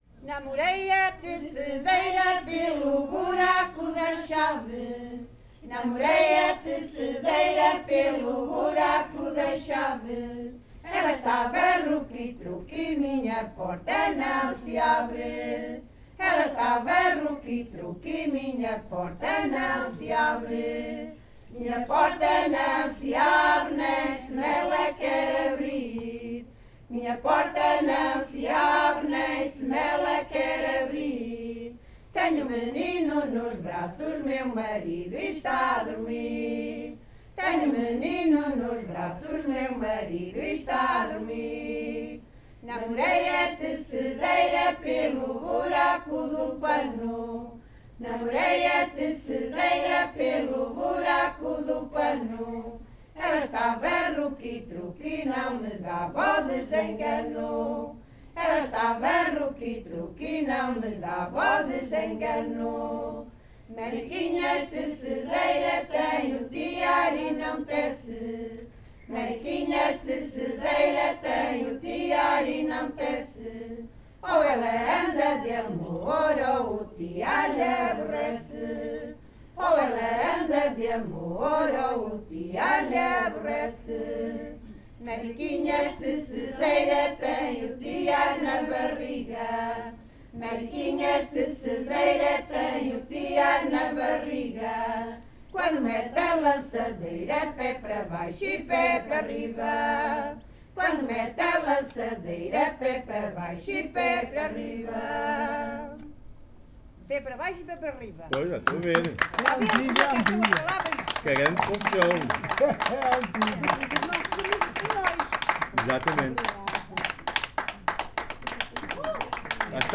Grupo Etnográfico de Trajes e Cantares do Linho de Várzea de Calde durante o encontro SoCCos em Portugal - Namorei a tecedeira pelo buraco da chave.